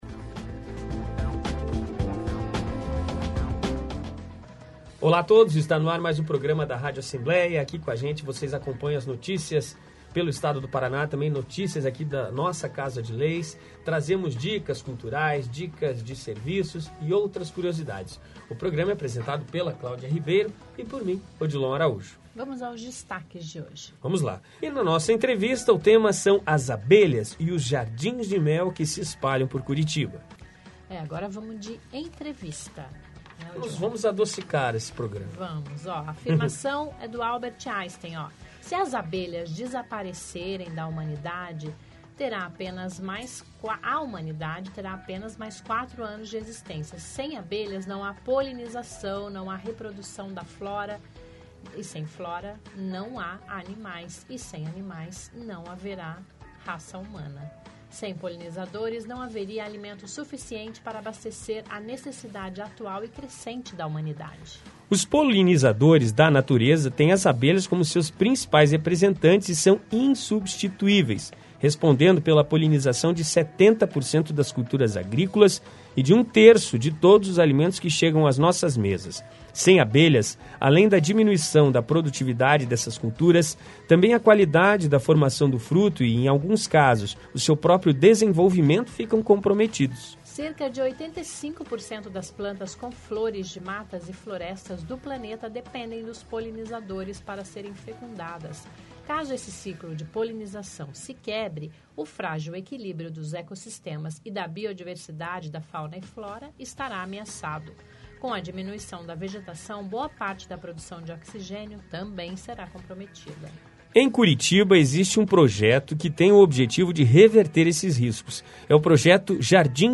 O nosso entrevistado fala do projeto Jardins de Mel, que coloca ninhos de abelhas nativas (sem ferrão), em parques e em alguns bairros de Curitiba.